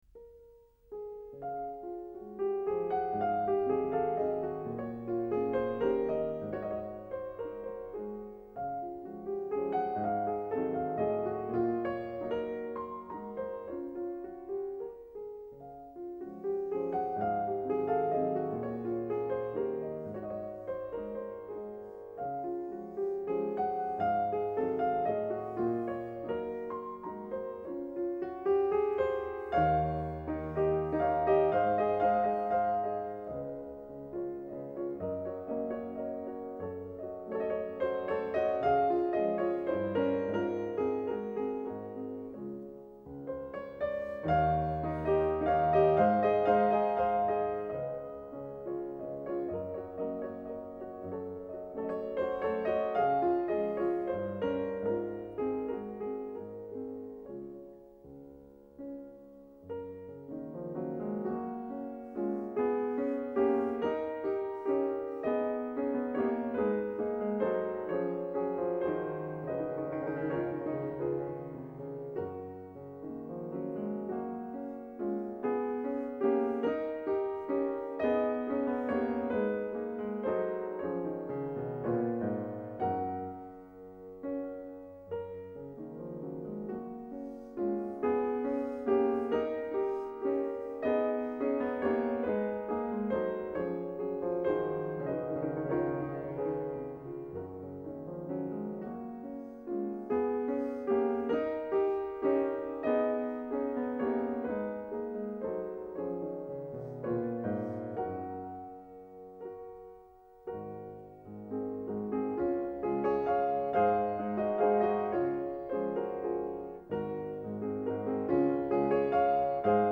Valses